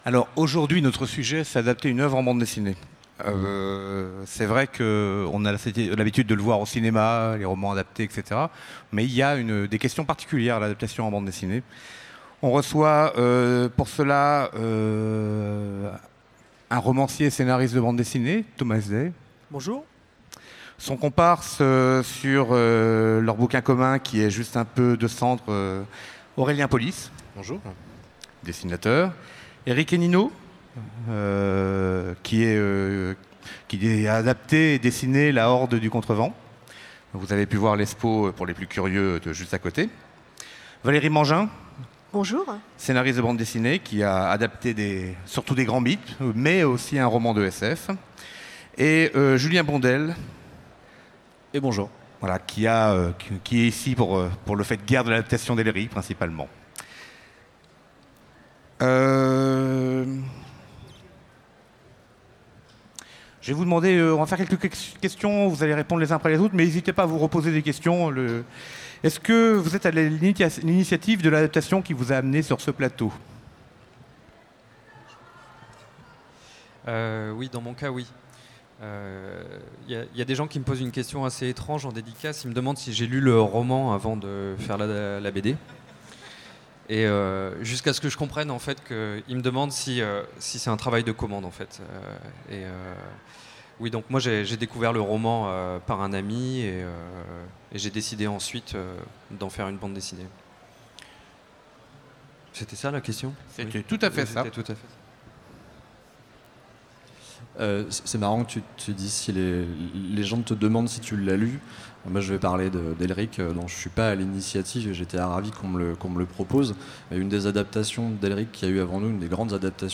Utopiales 2017 : Conférence Adapter une œuvre en bande dessinée